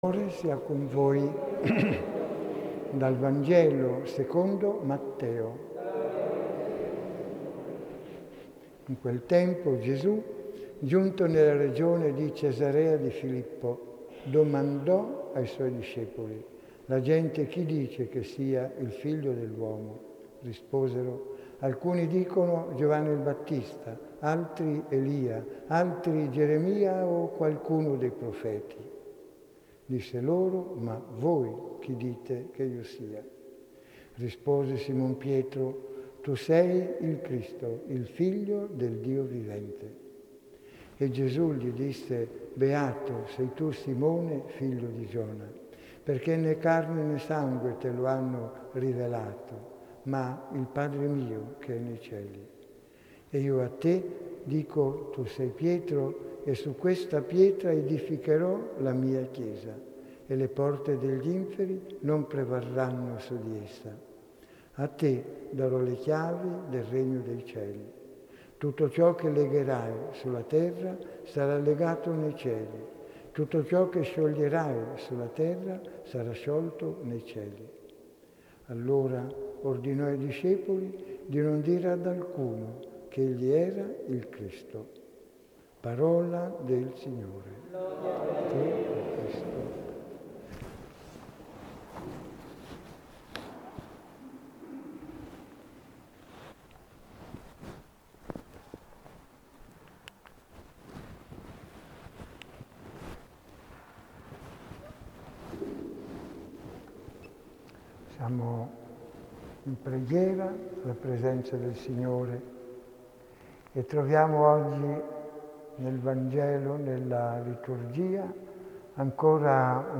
Domenica XX T.O. - 20.08.2017 Omelia su Matteo 15,21-28 .